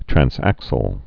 (trăns-ăksəl, trănz-)